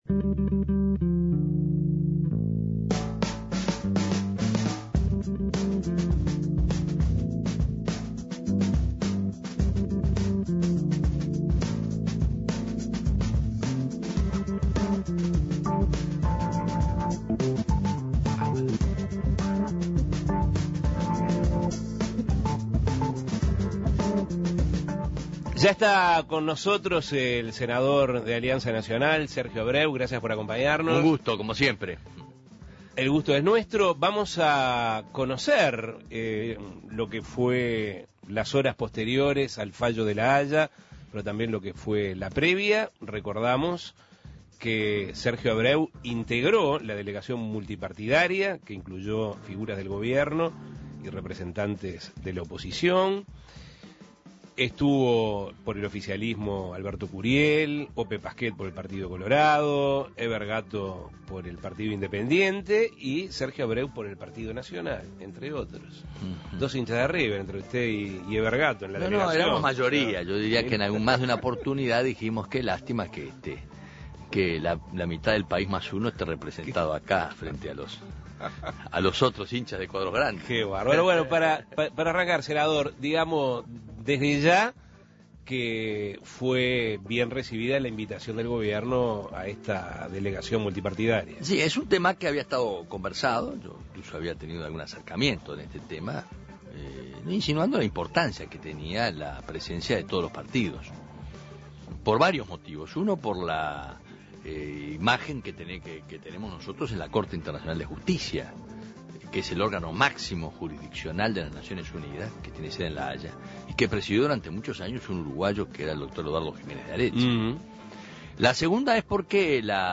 El senador de Alianza Nacional estuvo en Asuntos Pendientes hablando sobre su viaje a Holanda para participar de la audiencia en la Corte Internacional de Justicia de La Haya, donde se dio a conocer el fallo sobre el diferendo entre Argentina y Uruguay por la construcción de la planta de celulosa de la ex Botnia. Escuche la entrevista.